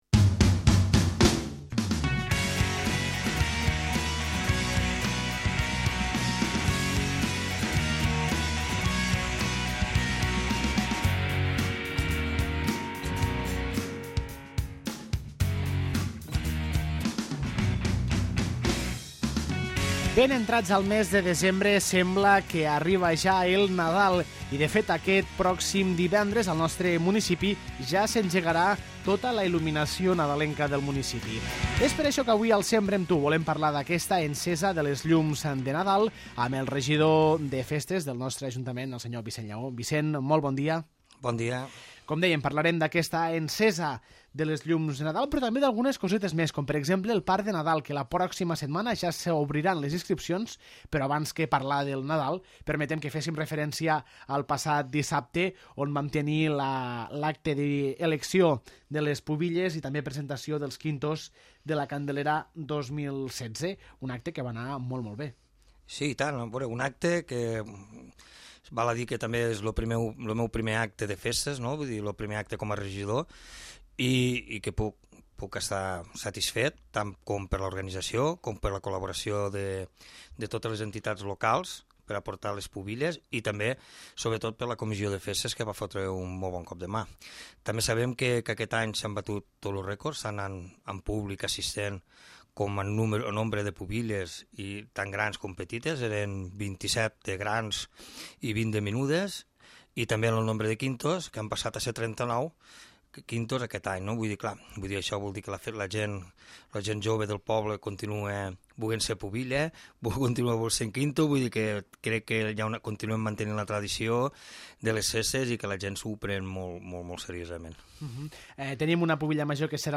Entrevistem al regidor de Festes, Vicenç Llaó, per parlar de l'Encesa de Llums de Nadal al Parc del Bon Repòs, de com va anar l'acte d'Elecció de la Pubilla Major de la Candelera 2016, i de l'inici de les inscripcions del Parc Infantil de Nadal.